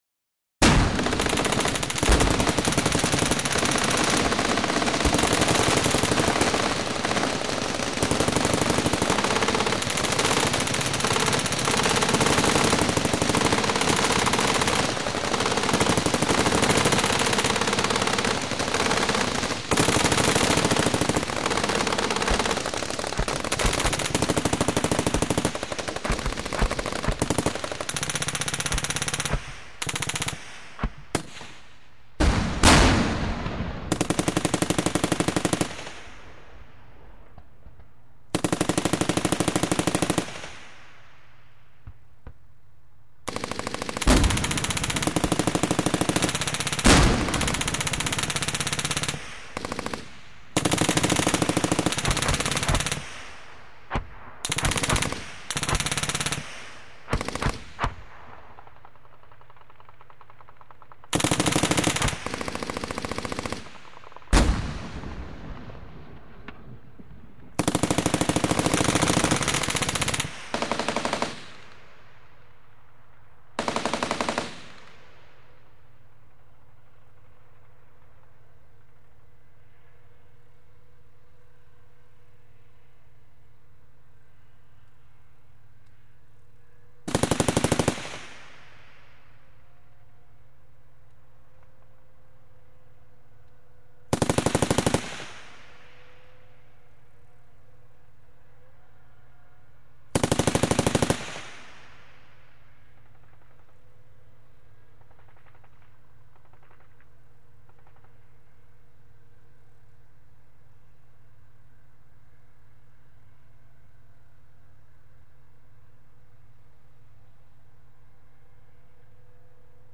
Звуки военной техники
Пулеметная и минометная перестрелка